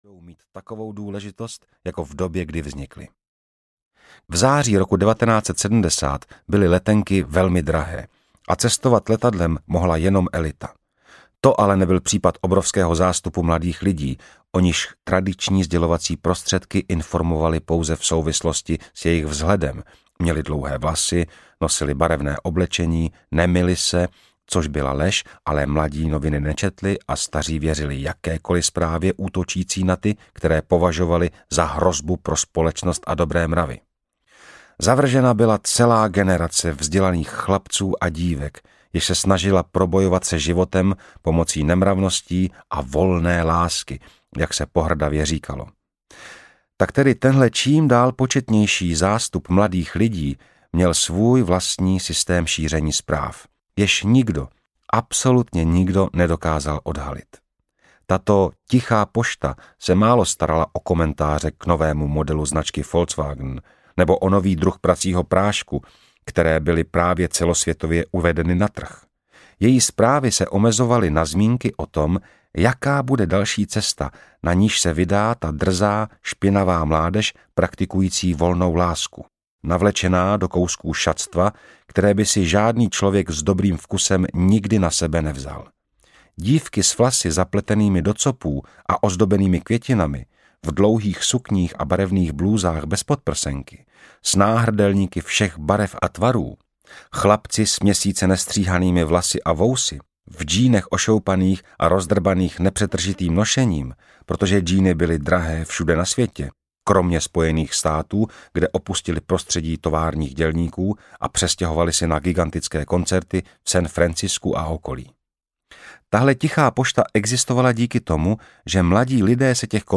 Hipík audiokniha
Ukázka z knihy
• InterpretMartin Pechlát